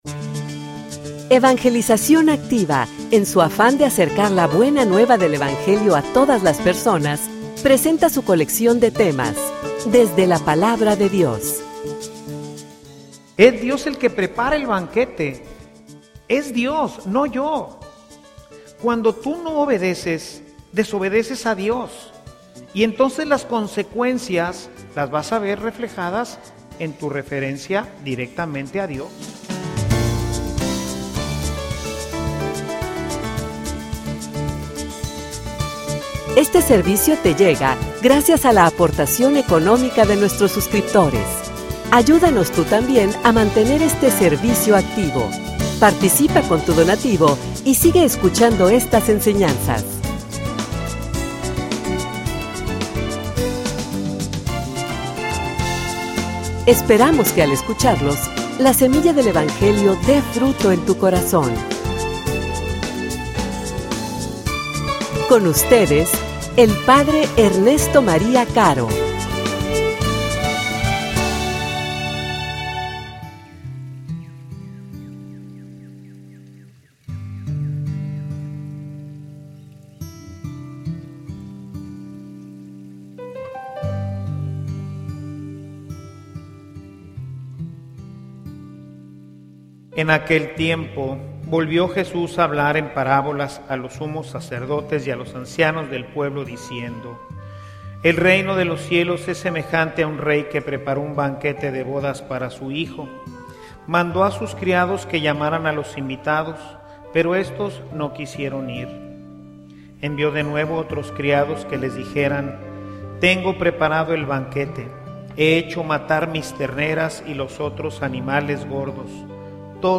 homilia_Es_Palabra_de_Dios.mp3